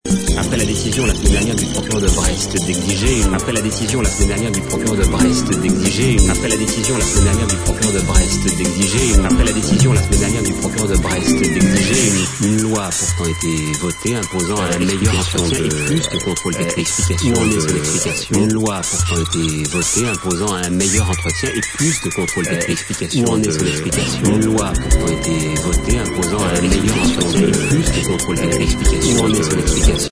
mp3 sonneries 30s
dauphins, baleines, TVstar